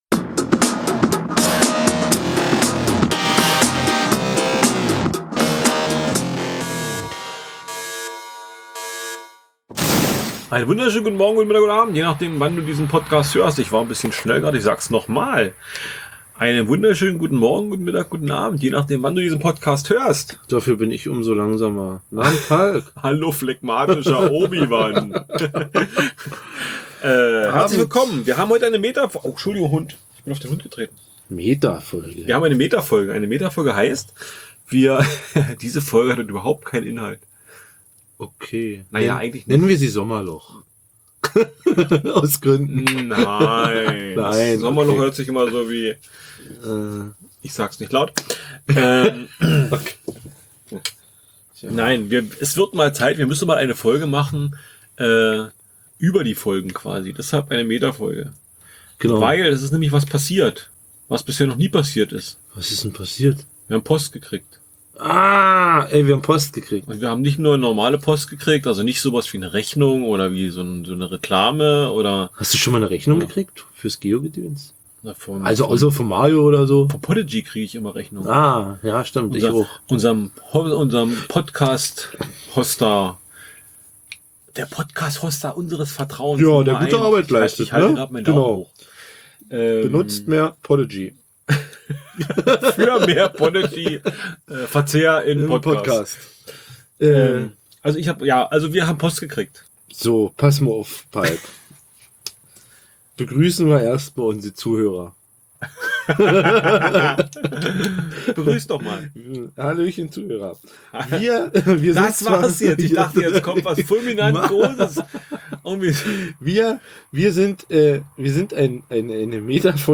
Dazu gibt es noch eine ganz gehörige Portion Atmo, denn wir habens uns abends draussen getroffen. Die Grillen zierpen, das Papier der Brezel und Nachos knistert, die Mate blubbert, herrlich.